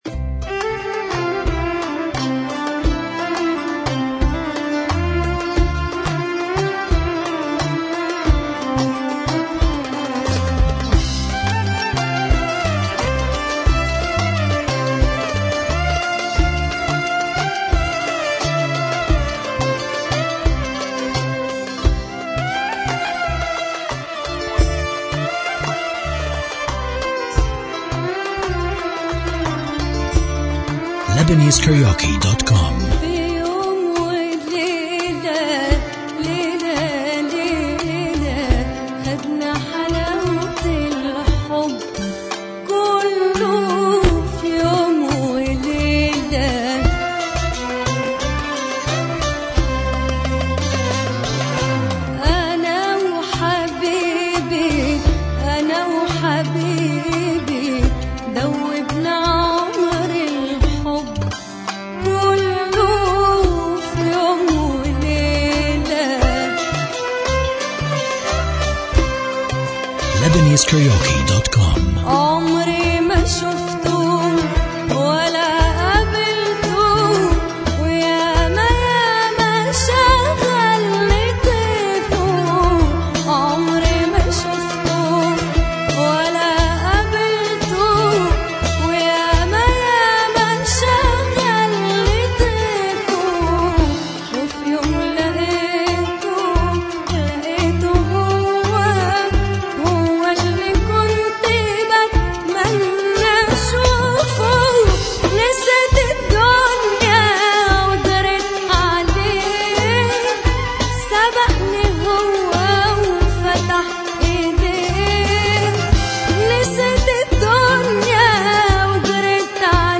Arabic Karaoke Talented Singers